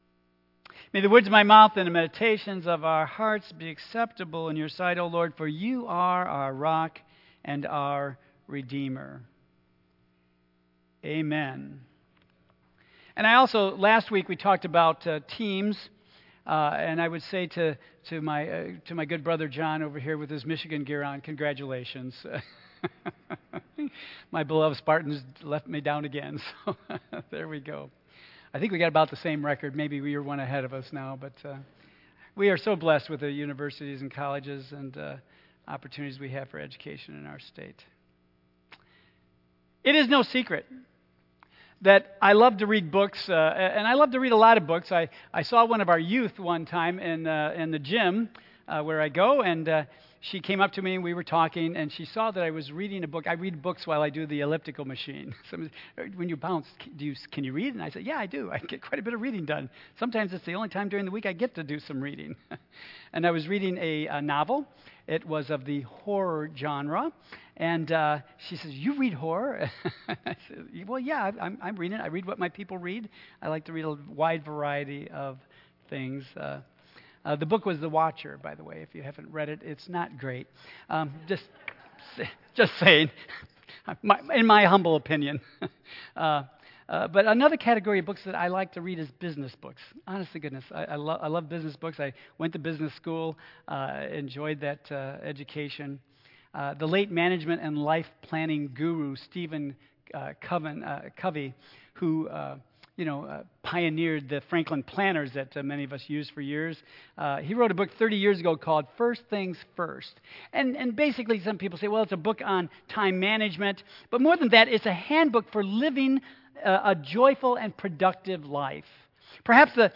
Tagged with Michigan , Sermon , Waterford Central United Methodist Church , Worship Audio (MP3) 10 MB Previous Your Team Next Milestones of Life